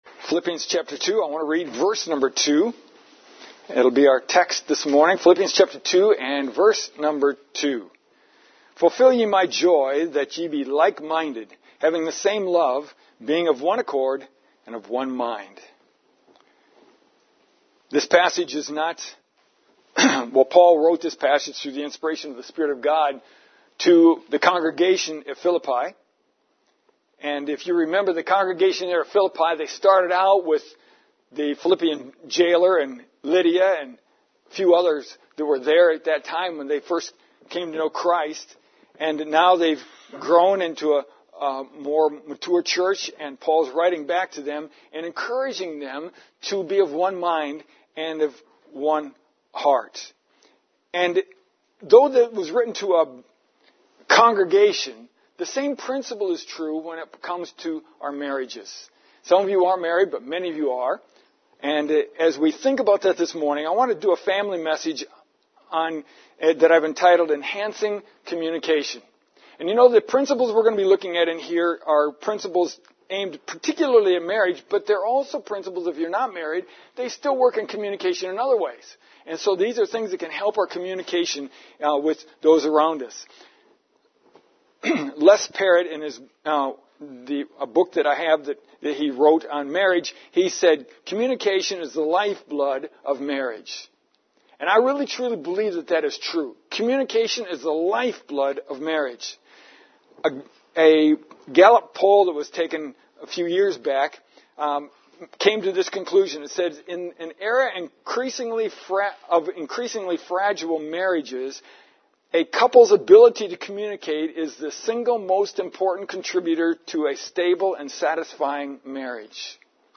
God wrote our text this morning to the local church at Philippi; however, the admonitions in this verse also apply to every marriage.